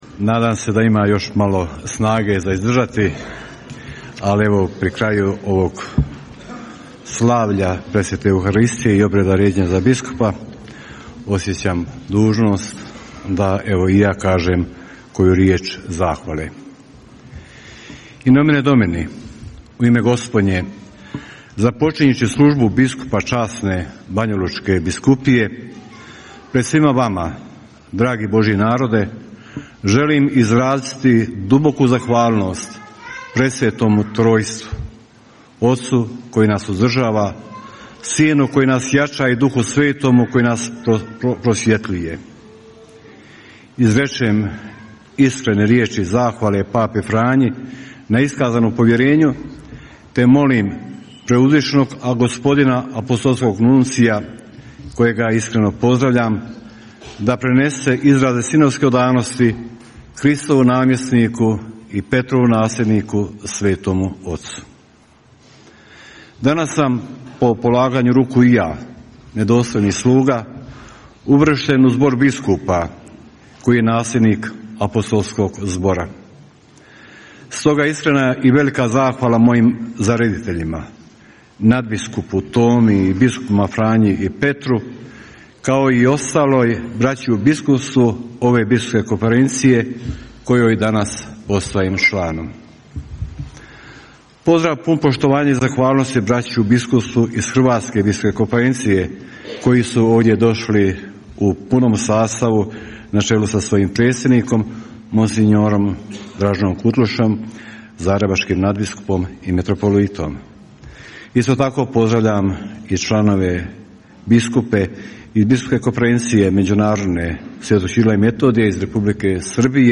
Na kraju Euharistijskog slavlja i biskupskog ređenja, 2. ožujka 2024. u katedrali sv. Bonaventure u Banjoj Luci, zaređeni biskup banjolučki mons. Željko Majić uputio je zahvalnu riječ koju prenosimo u cijelosti: